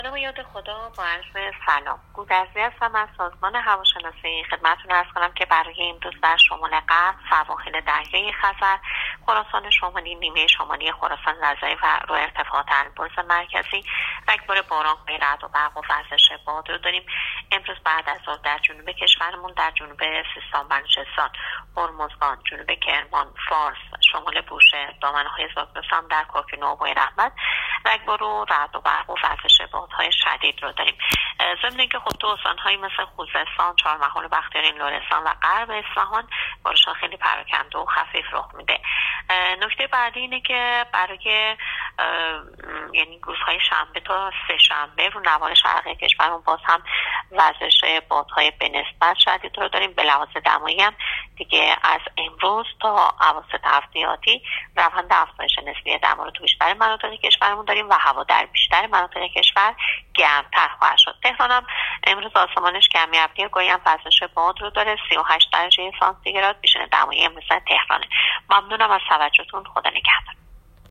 گزارش رادیو اینترنتی از آخرین وضعیت آب و هوای۳ مرداد